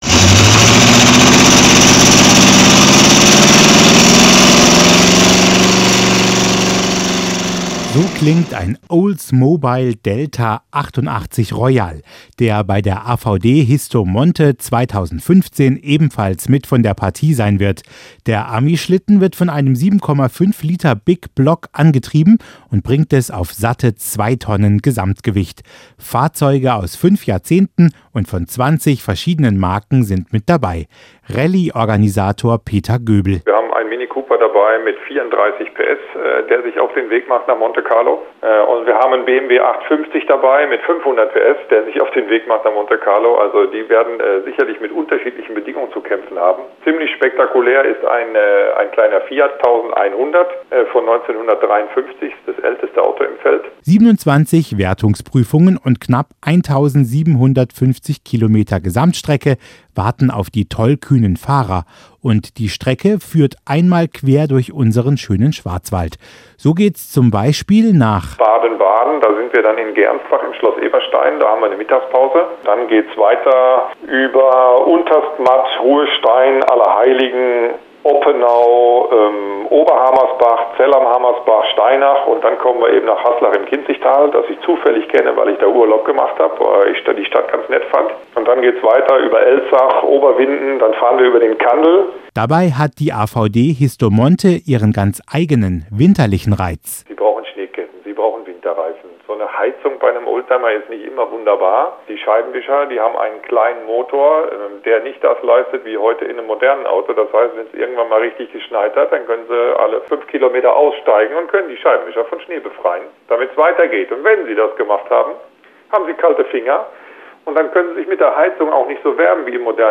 AvD-Histo-Monte-Beitrag-für-Schwarzwaldradio.mp3